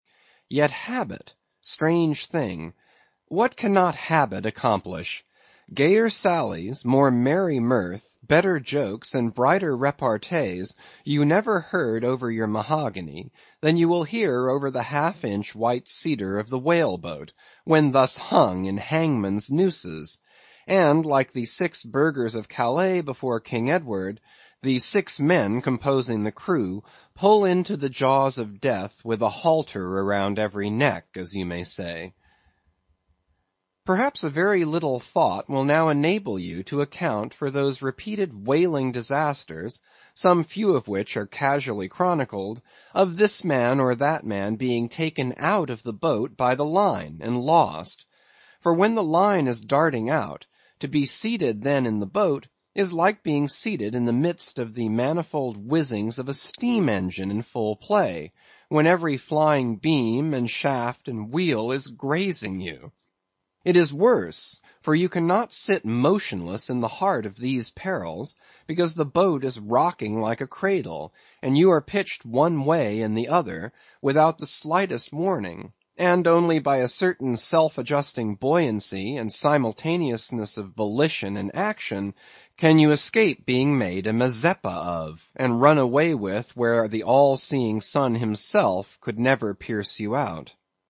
英语听书《白鲸记》第594期 听力文件下载—在线英语听力室